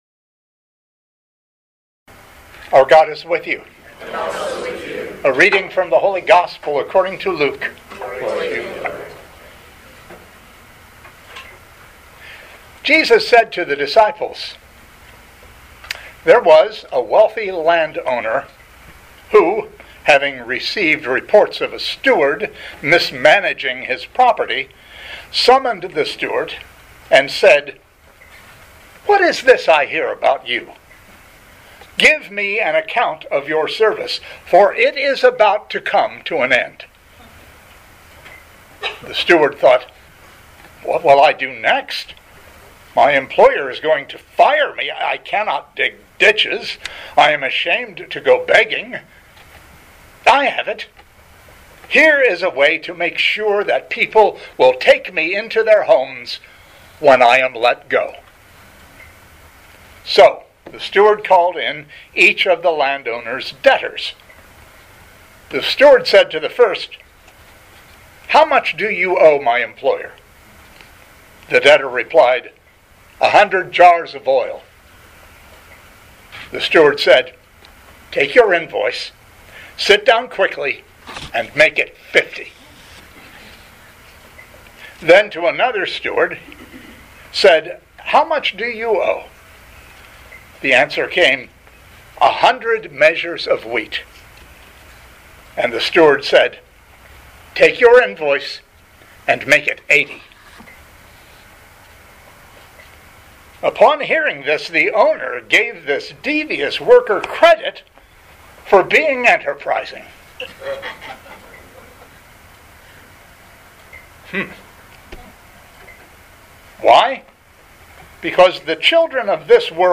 Living Beatitudes Community Homilies: Crisis and Blessing